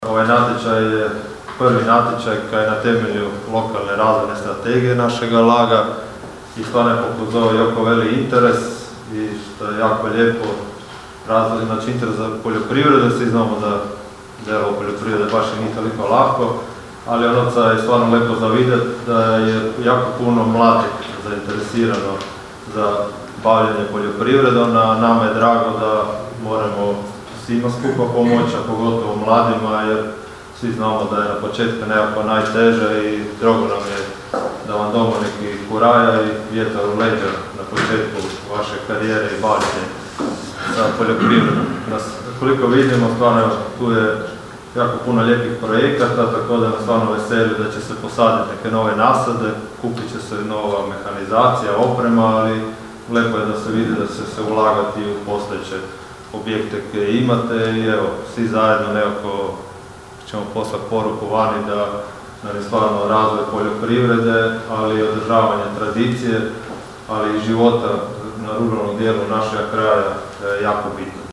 Obraćajući se dobitnicima sredstava, gradonačelnik Labina i predsjednik LAG-a Istočna Istra Donald Blašković istaknuo je: (